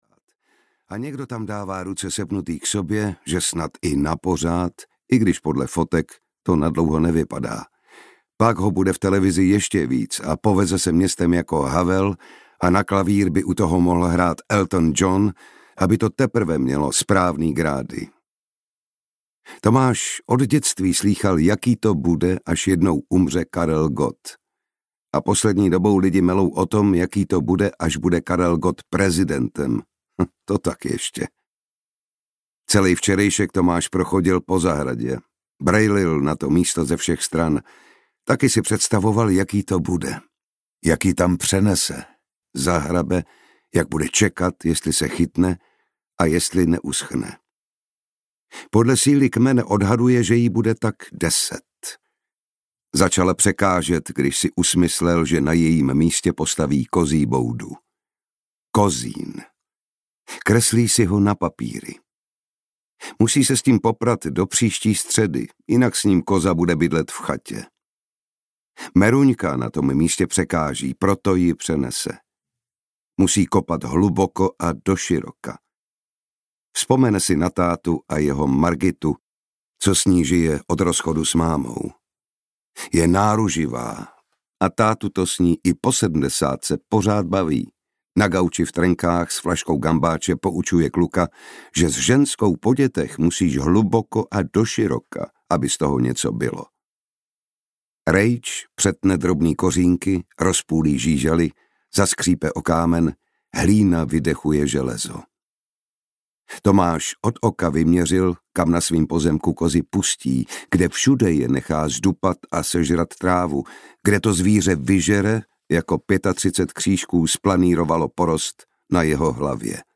Krajina roztavených zvonů audiokniha
Ukázka z knihy
• InterpretJan Šťastný